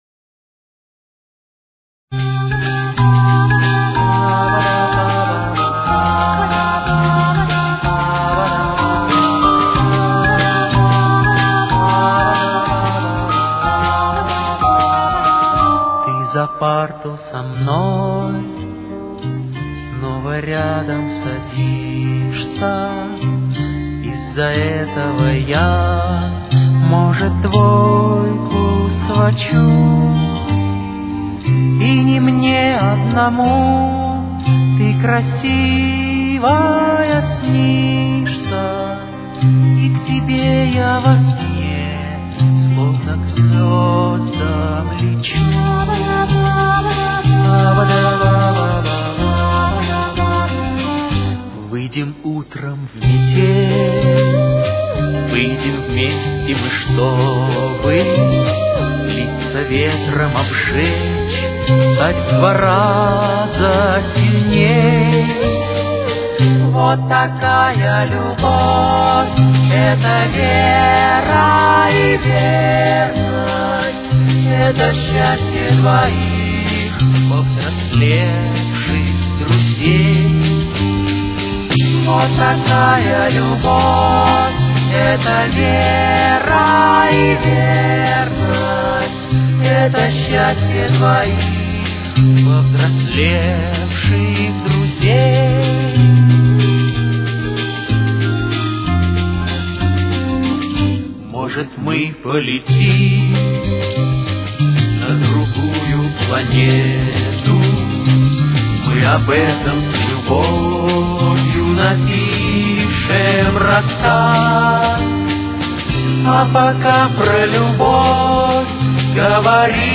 с очень низким качеством